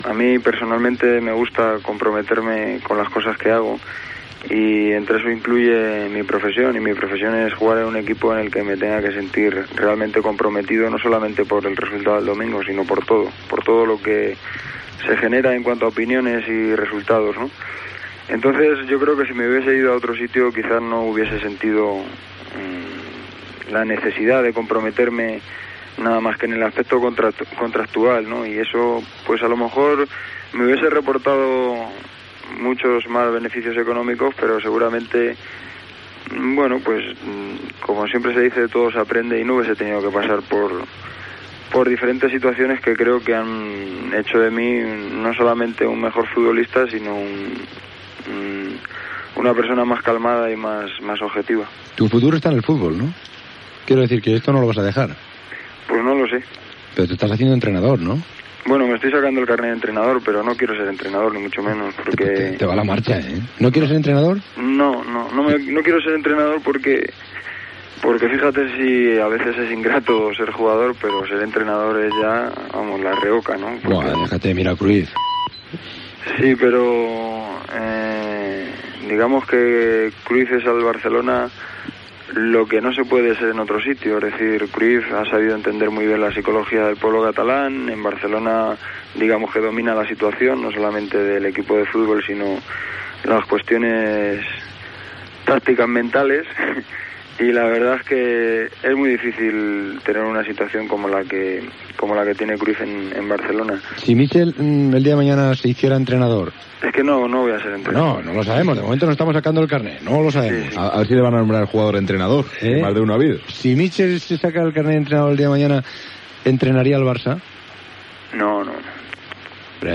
Fragment d'una entrevista al jugador de futbol Michel (José Miguel González Martín) del Real Madrid
Esportiu